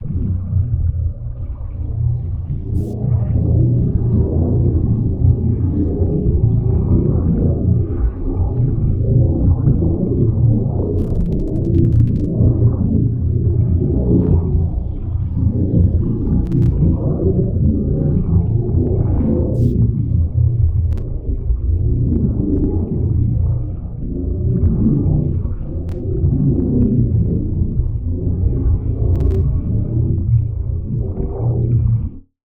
sphere_idle.ogg.bak